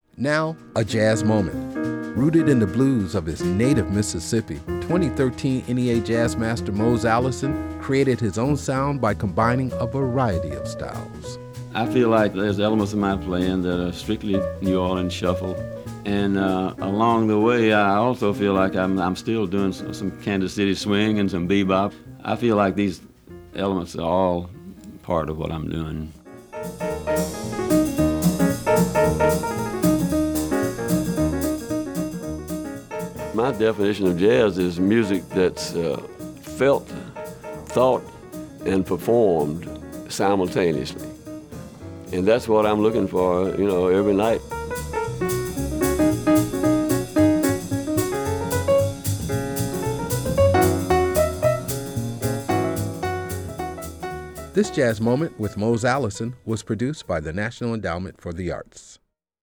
In this Jazz Moment, pianist and singer Mose Allison describes how he has drawn on various jazz traditions to develop his own musical style. [00:59] Music Credit: Excerpt of “Train” and “Saturday” written and performed by Mose Allison from the album, Back Country Suite, used courtesy of Concord Records, and used by permission of Audre Mae Music (BMI). Audio Credit: Excerpt of Allison from an interview with Ben Sidran featured on the CD, Talking Jazz Volume 19, used courtesy of Ben Sidran.